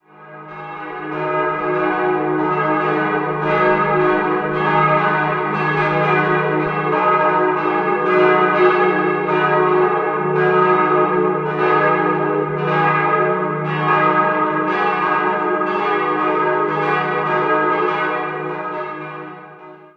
3-stimmiges TeDeum-Geläute: dis'-fis'-gis'
Ein Geläute mit interessanter Klangabstrahlung: Die Glockenstube ist an den vier Wandseiten vollständig geschlossen, der Klang gelangt stattdessen durch Öffnungen im Boden der Glockenstube nach außen.